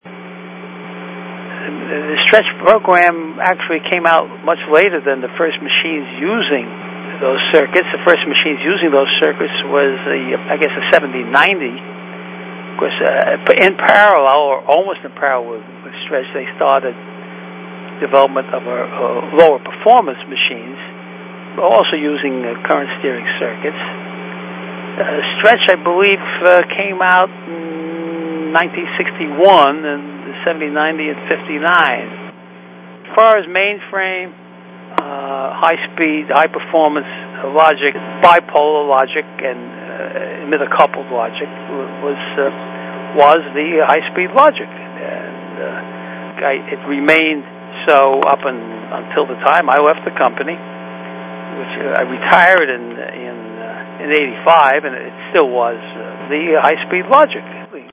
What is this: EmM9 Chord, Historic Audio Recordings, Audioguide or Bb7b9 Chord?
Historic Audio Recordings